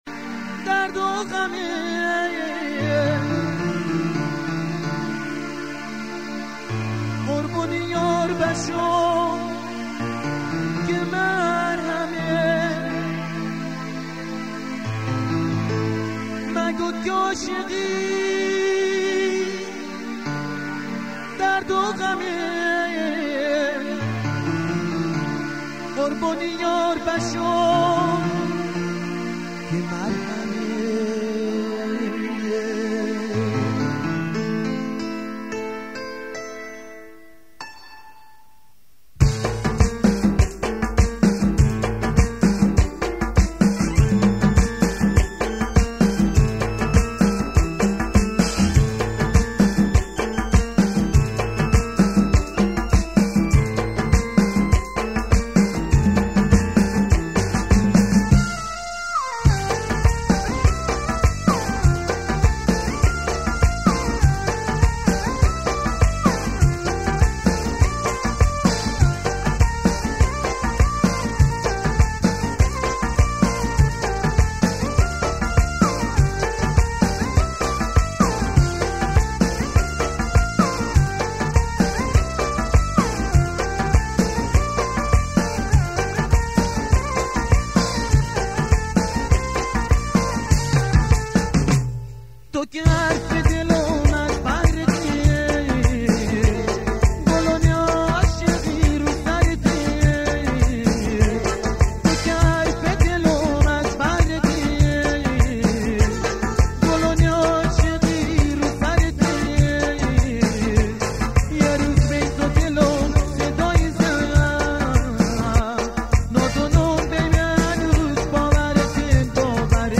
ترانه قدیمی بندری